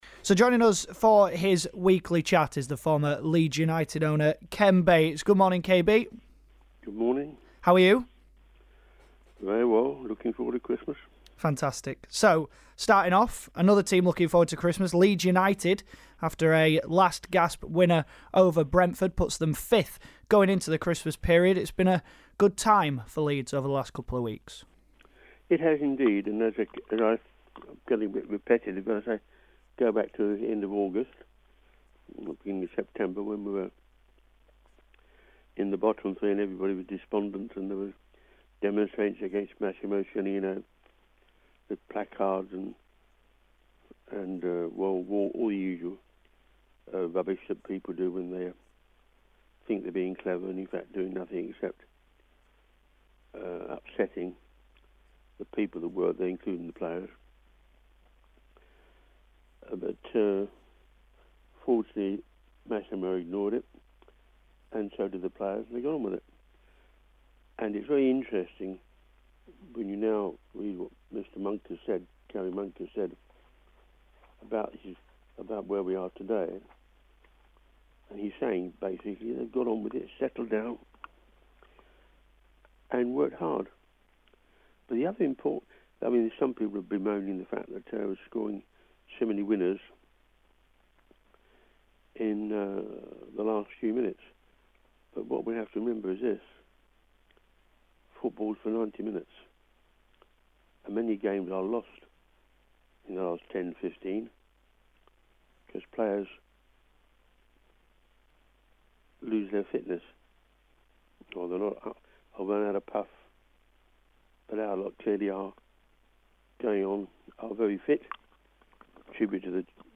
Ken Bates Interview 22/12